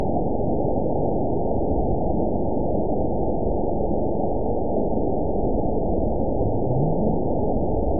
event 917052 date 03/13/23 time 22:55:23 GMT (2 years, 1 month ago) score 9.55 location TSS-AB01 detected by nrw target species NRW annotations +NRW Spectrogram: Frequency (kHz) vs. Time (s) audio not available .wav